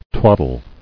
[twad·dle]